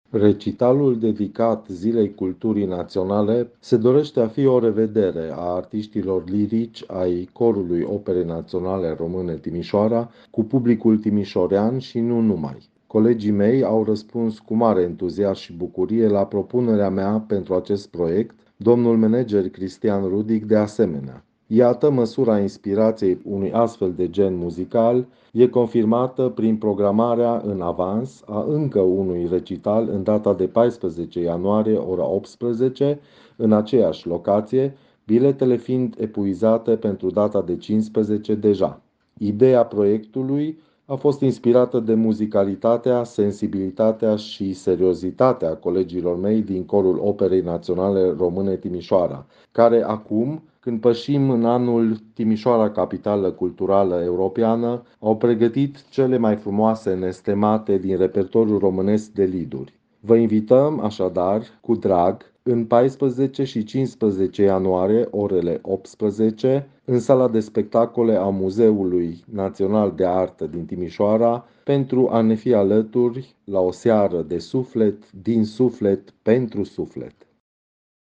baritonul
interviu în exclusivitate pentru Radio Timișoara